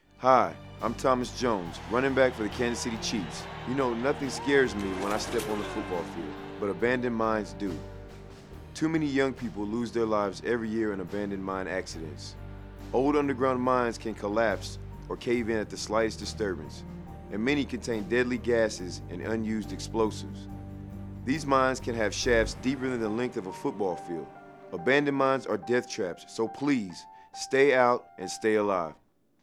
Thomas Jones of the Kansas City Chiefs prepared PSAs for the US Department of Labor